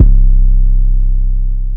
808 [had enough].wav